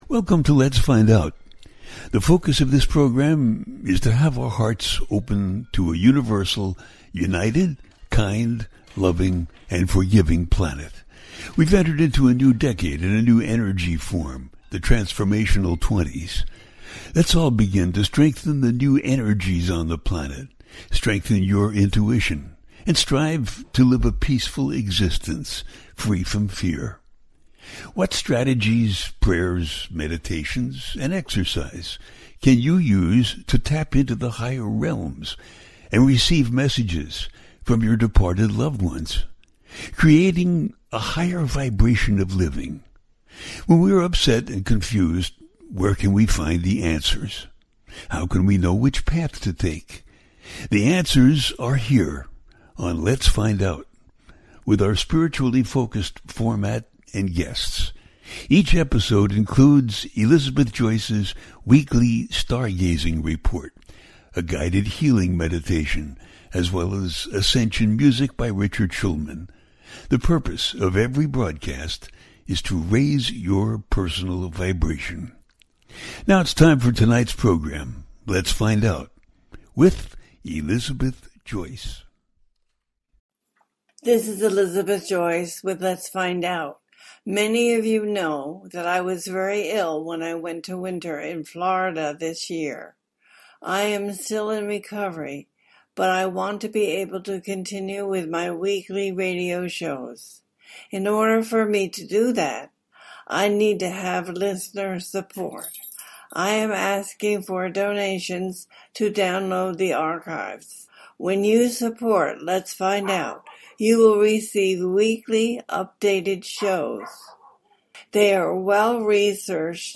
Talk Show Episode, Audio Podcast, Lets Find Out and Let’s Talk on , show guests , about let’s talk about it, categorized as Earth & Space,News,Paranormal,Politics & Government,Science,Society and Culture,Spiritual,Astrology,Theory & Conspiracy
This show brings a series of fascinating interviews with experts in the field of metaphysics.
The listener can call in to ask a question on the air.
Each show ends with a guided meditation.